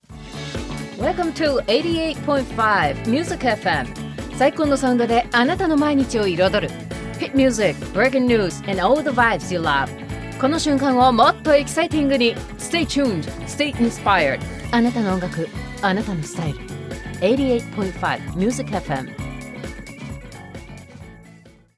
Radiobildgebung
Ihre strahlende, tiefe Stimme hat eine ausgeprägte Überzeugungskraft und einen vertrauenerweckenden Klang.